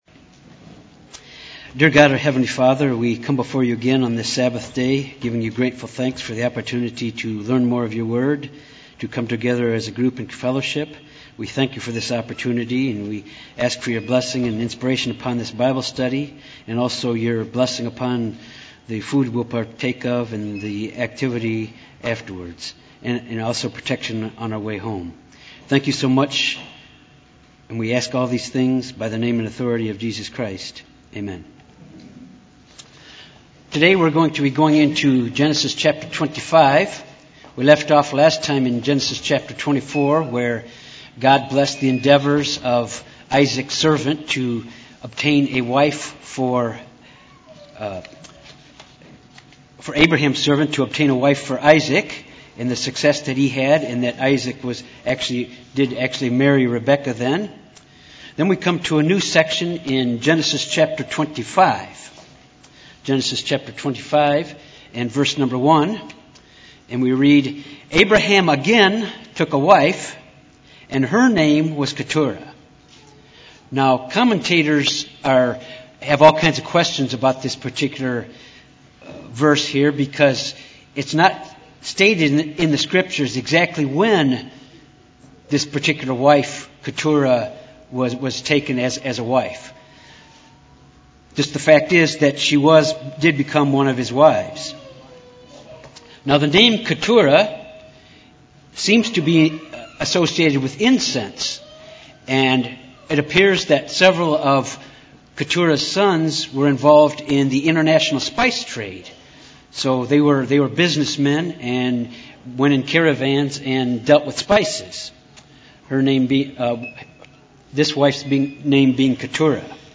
This Bible study focuses on Genesis 25-27.
Given in Little Rock, AR
UCG Sermon Studying the bible?